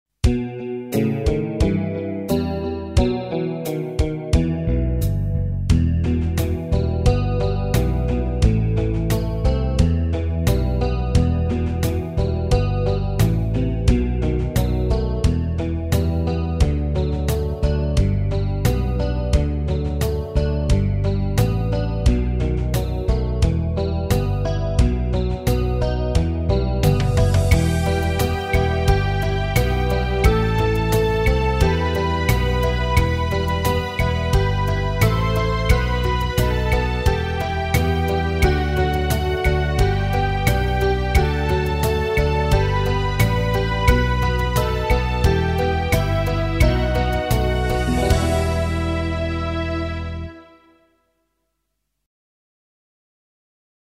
Meespeel CD